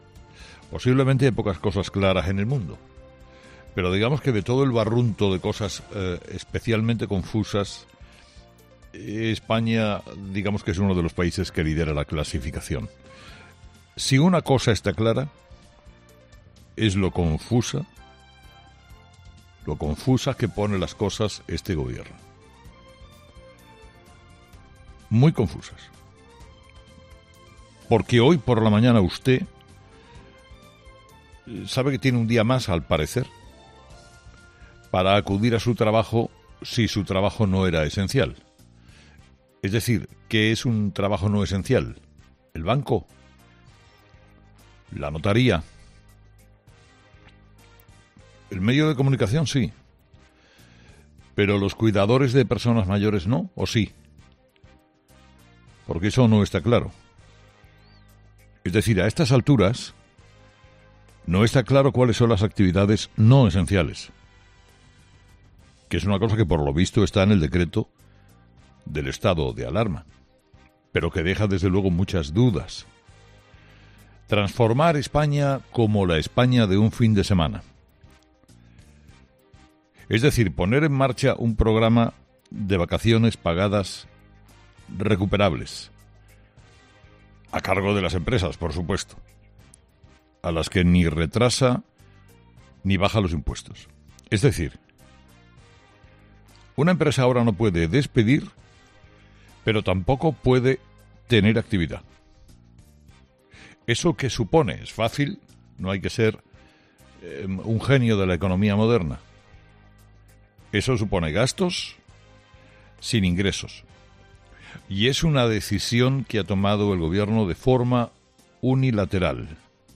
En su monólogo de las seis de la mañana, Carlos Herrera ha comentado la decisión del Gobierno de paralizar toda economía no esencial mediante un decreto ley aprobado este domingo.